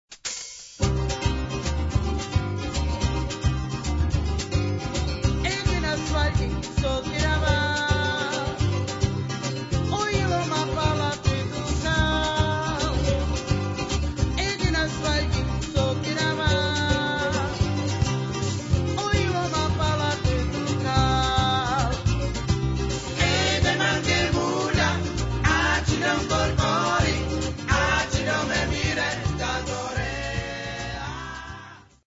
romská zpěvačka
produkují vynikající romský folkrock.